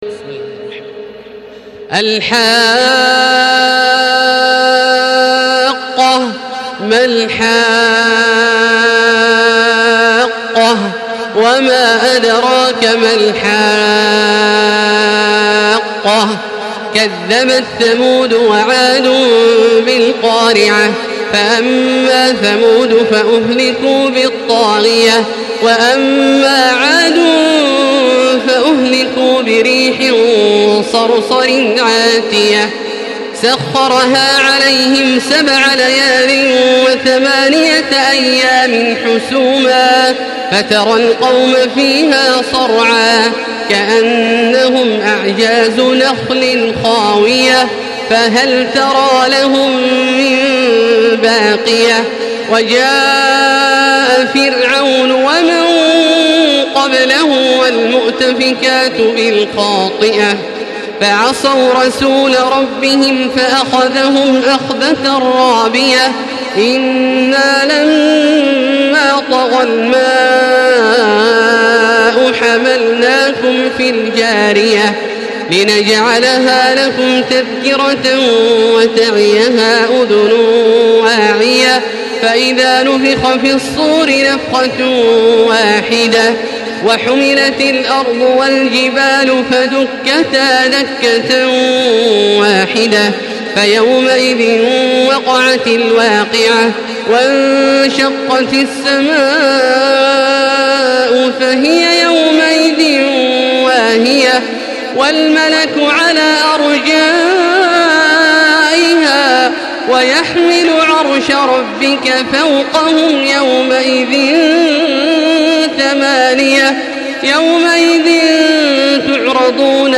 Surah Al-Haqqah MP3 in the Voice of Makkah Taraweeh 1435 in Hafs Narration
Listen and download the full recitation in MP3 format via direct and fast links in multiple qualities to your mobile phone.
Murattal